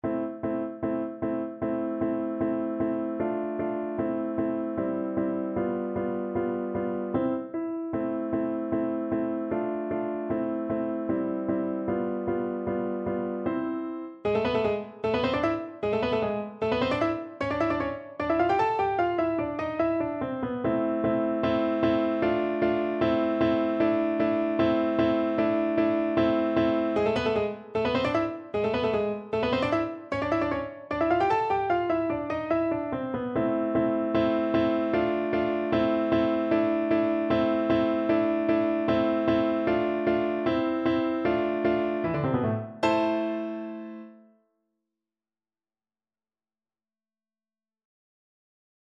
A minor (Sounding Pitch) (View more A minor Music for Viola )
2/4 (View more 2/4 Music)
Allegro scherzando (=152) (View more music marked Allegro)
Classical (View more Classical Viola Music)